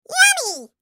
Звук ямми мультяшный